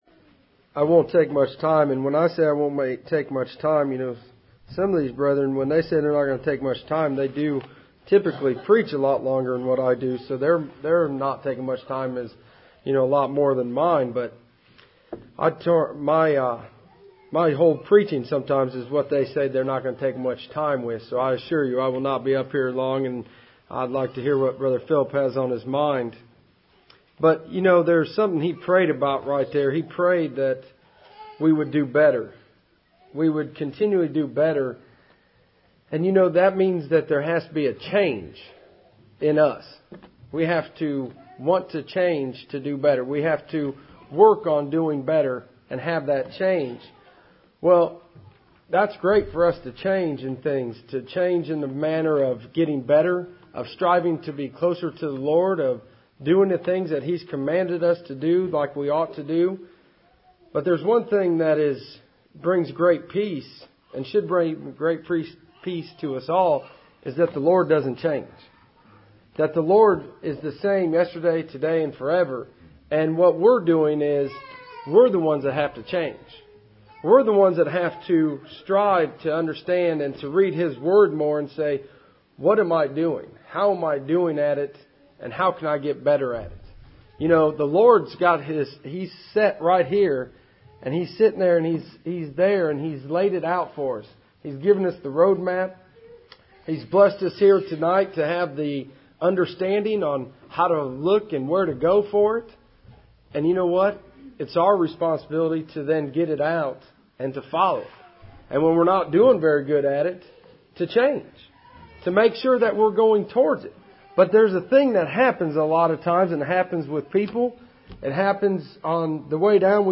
1 Corinthians 3:12-13 Service Type: Cool Springs PBC Sunday Evening %todo_render% « Sovereignty of God